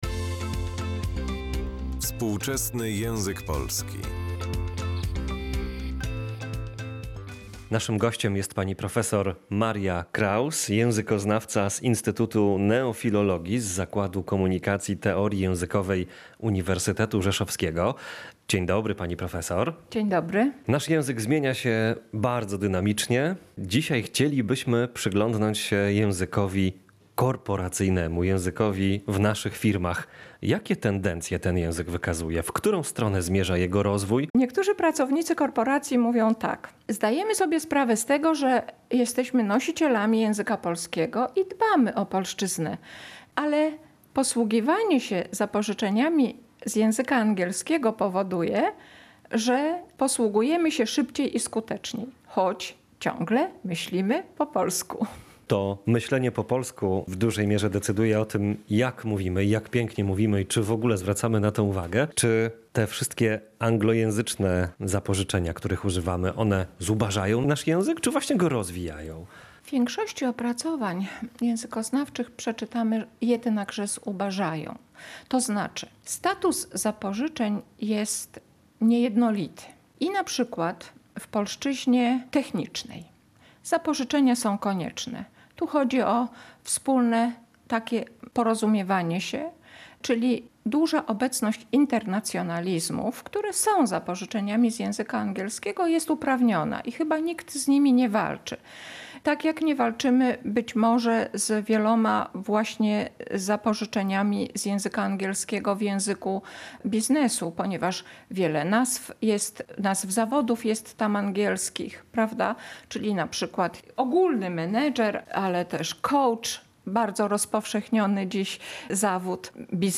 Właśnie o tym rozmawiamy w tym odcinku cyklu „Współczesny język polski”